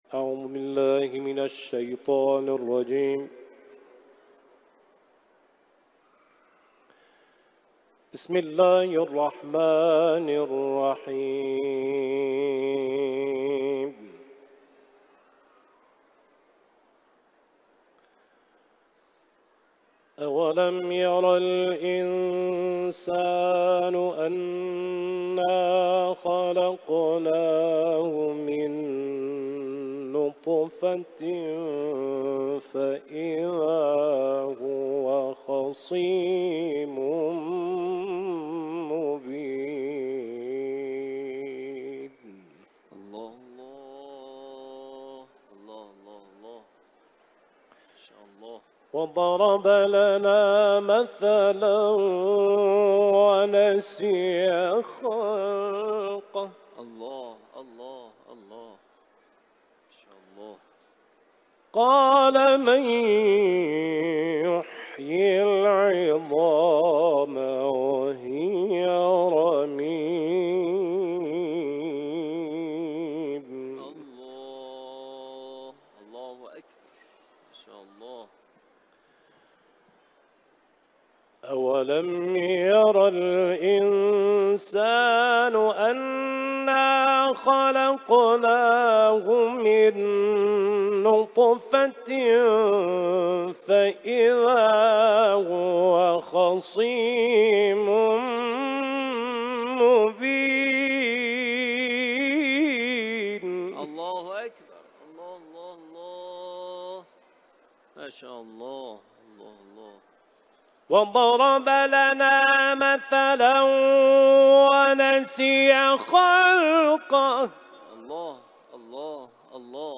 سوره یاسین ، تلاوت قرآن